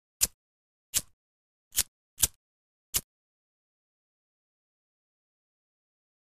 Lighter ( Dead ); Lighter Strikes With No Flame.